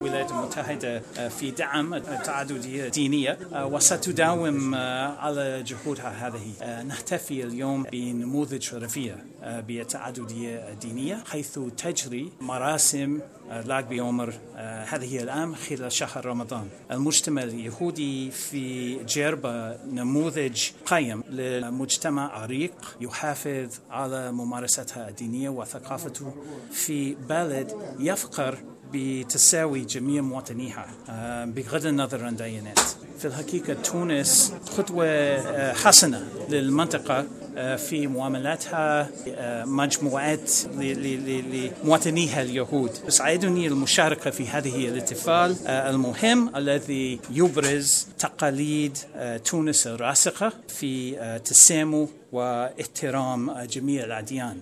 L’Ambassadeur des États-Unis à Tunis, Donald Blome, a indiqué ce mercredi 22 mai 2019, en marge des festivités pour le pèlerinage de la Ghriba à Djerba (Médenine), que la Tunisie est un exemple du pluralisme religieux.